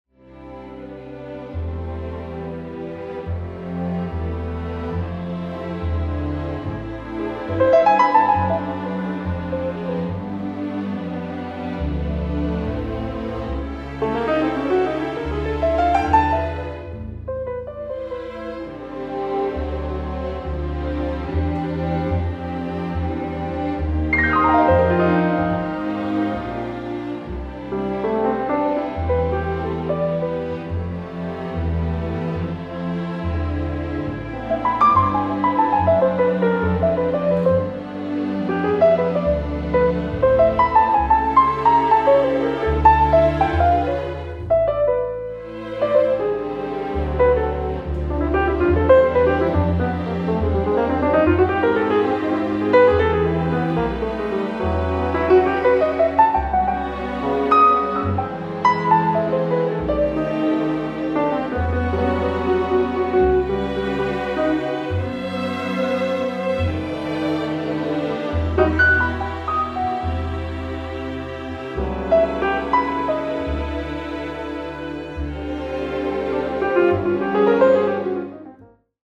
Piano with strings